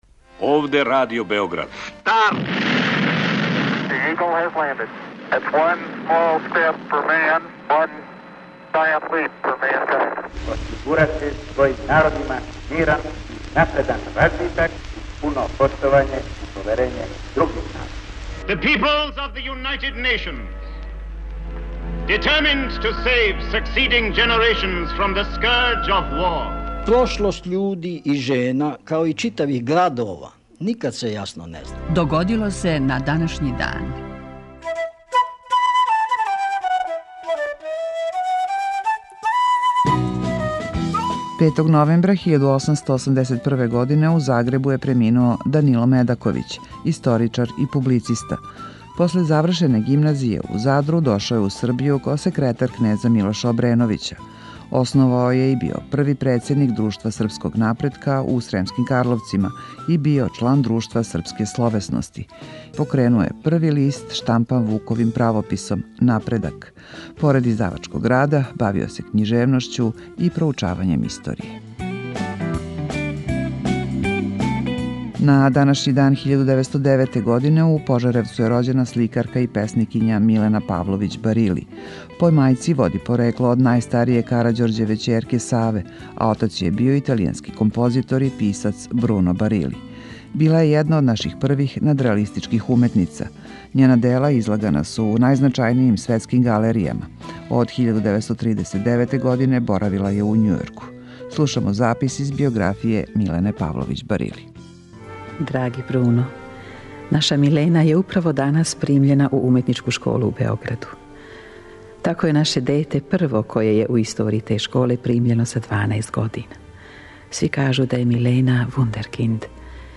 У 5-томинутном прегледу, враћамо се у прошлост и слушамо гласове људи из других епоха.